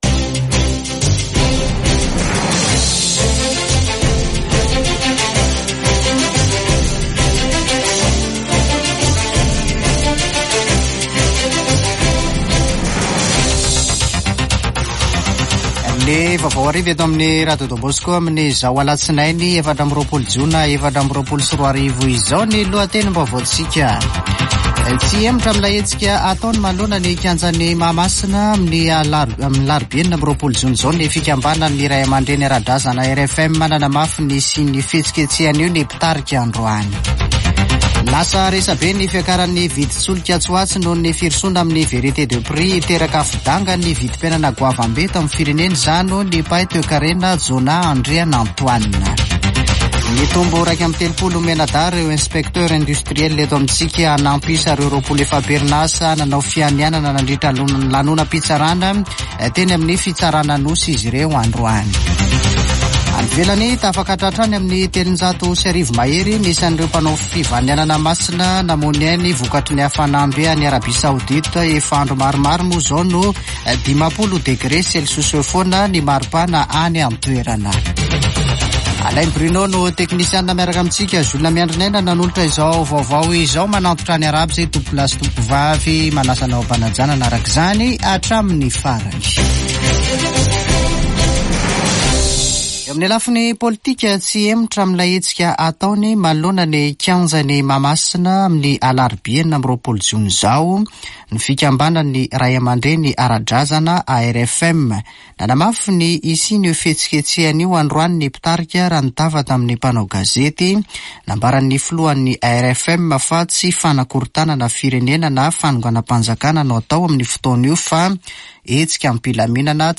[Vaovao hariva] Alatsinainy 24 jona 2024